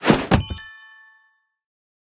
tutorial_book_appears_v01.ogg